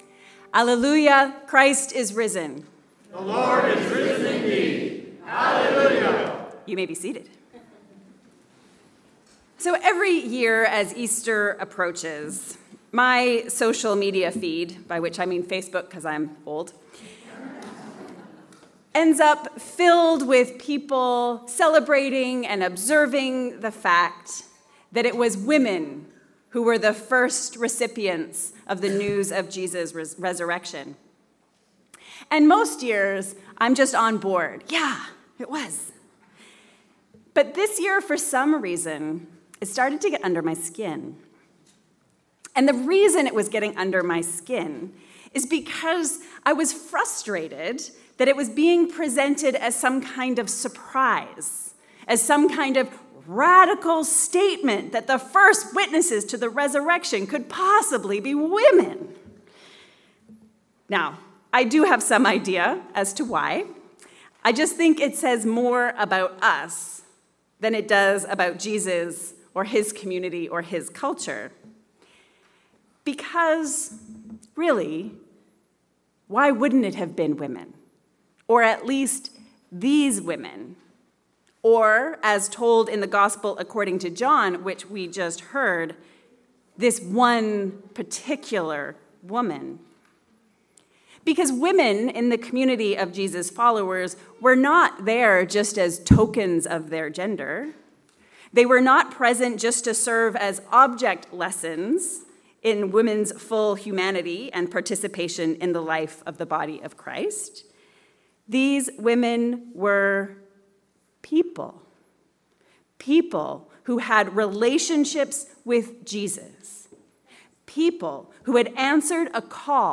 The first resurrection story – but not the last resurrection story. An Easter sermon on Mary Magdalene and the empty tomb.